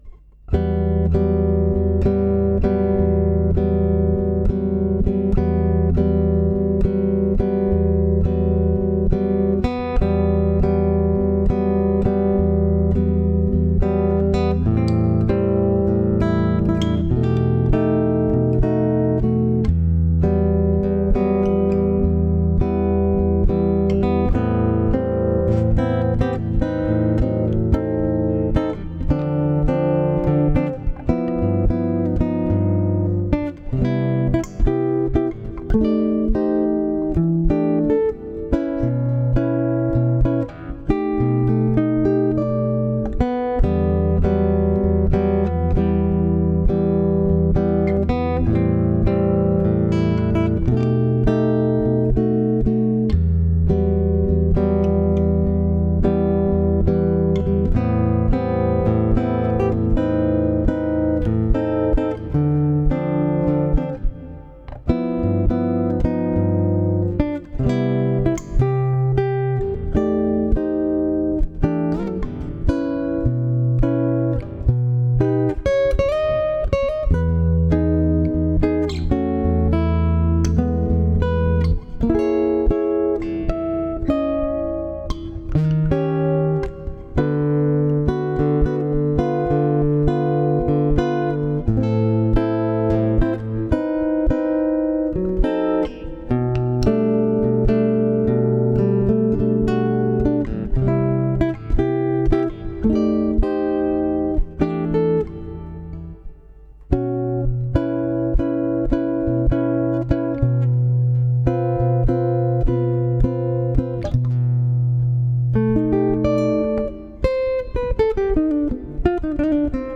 BOSSA NOVA GUITAR SOLO versione slowly
bossa CHITARRA